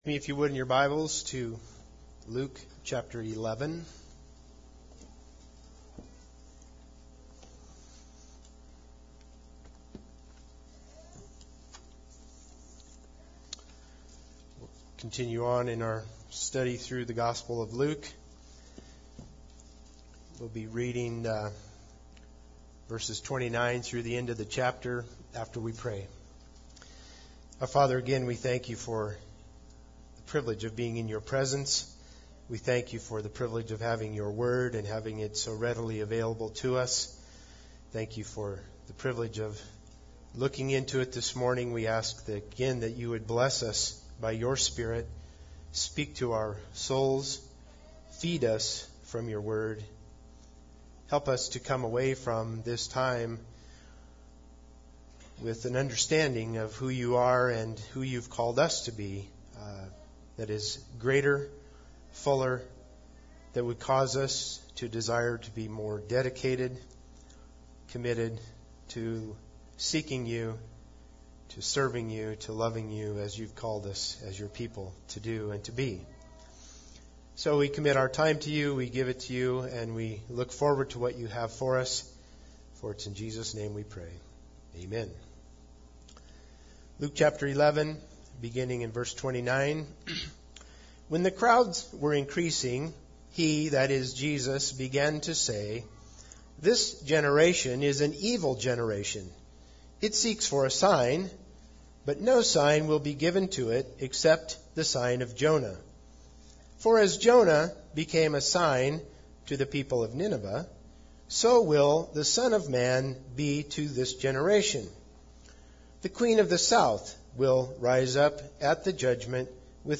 Passage: Luke 11:29-53 Service Type: Sunday Service Bible Text